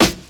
• Classic Hip-Hop Steel Snare Drum Sound E Key 31.wav
Royality free snare sound tuned to the E note. Loudest frequency: 2297Hz
classic-hip-hop-steel-snare-drum-sound-e-key-31-SCV.wav